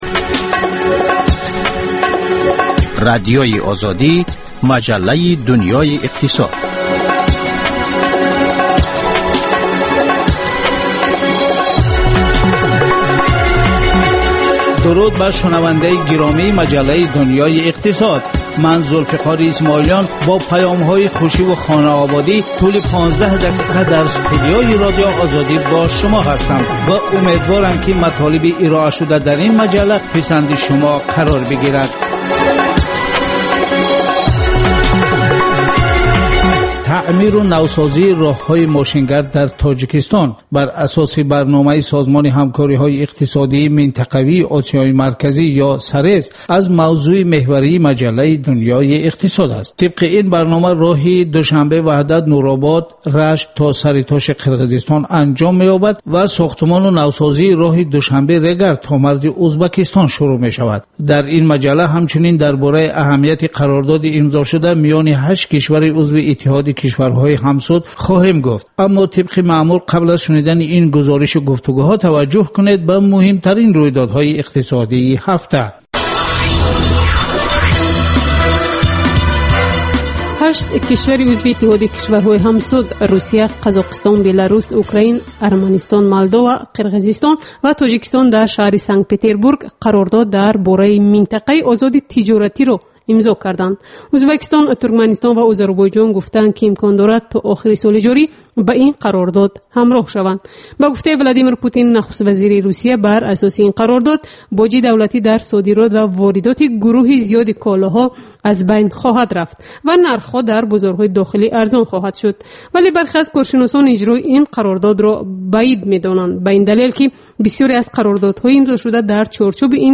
Хабарҳои иқтисодии Тоҷикистон, минтақа ва ҷаҳон. Баррасии фарояндаҳо ва падидаҳои муҳим дар гуфтугӯ бо коршиносон.